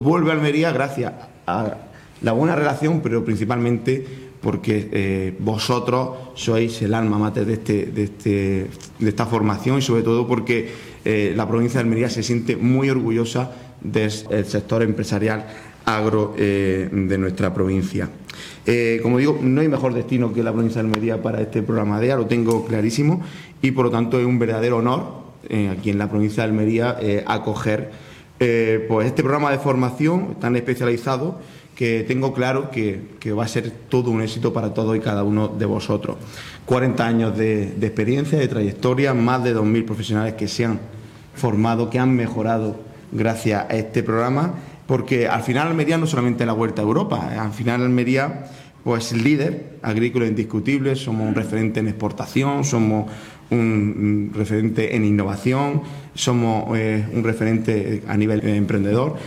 La alianza entre la Institución Provincial y la Fundación San Telmo se ha materializado con la celebración del Programa DEA entre abril y noviembre con el aforo completo Durante su intervención, el presidente ha subrayado que Almería es el «destino natural» para una formación de esta categoría.
07-04_presidente_s._telmo.mp3.mp3